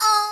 WailPreview.wav